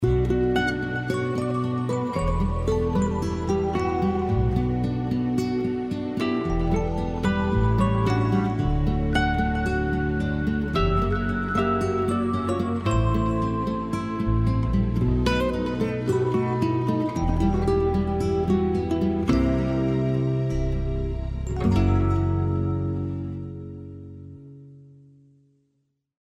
• Качество: 192, Stereo
гитара
без слов
инструментальные
романтичные
Красивый, романтичный рингтон.